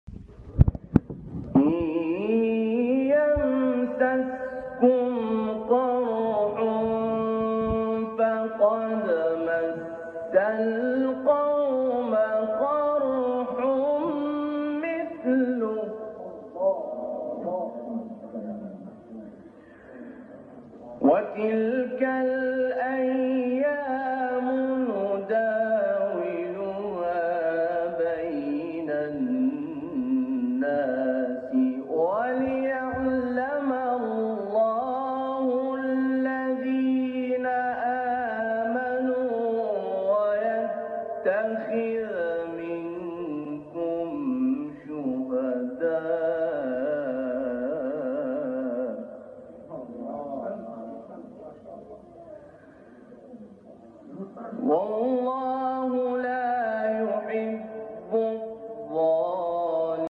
گروه فعالیت‌های قرآنی: فرازهای صوتی از قراء برجسته جهان اسلام را می‌شنوید.
مقطعی از شعبان عبدالعزیز صیاد/ سوره مریم